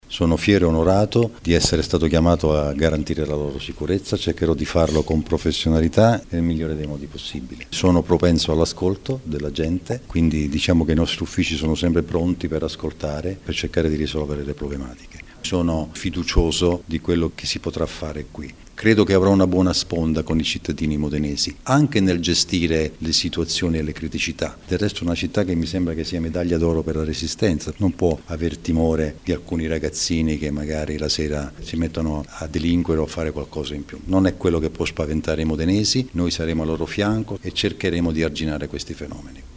Ha definito Modena una “città impegnativa” ma è pronto a mettersi a disposizione dei cittadini. Sentiamolo intervistato